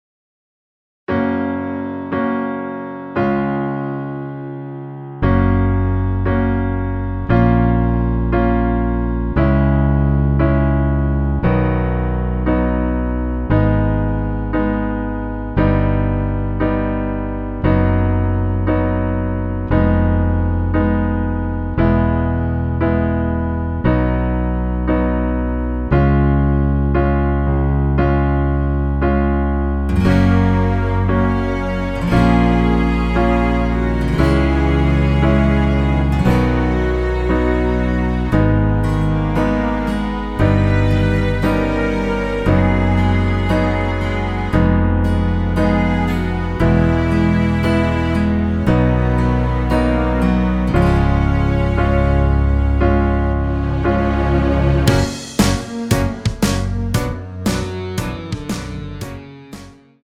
전주 없이 시작 하는 곡이라 노래 하시기 편하게 전주 2마디 만들어 놓았습니다.
F#
앞부분30초, 뒷부분30초씩 편집해서 올려 드리고 있습니다.
곡명 옆 (-1)은 반음 내림, (+1)은 반음 올림 입니다.